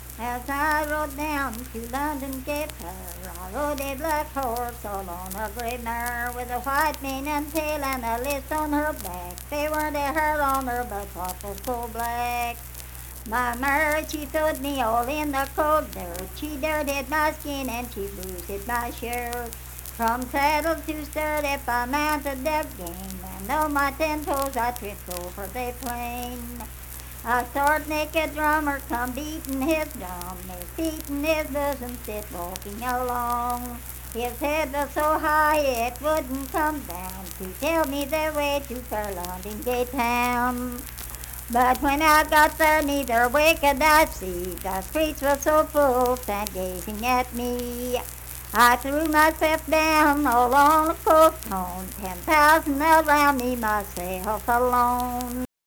Unaccompanied vocal music performance
Children's Songs, Humor and Nonsense
Voice (sung)